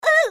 slayer_f_voc_hit_b.mp3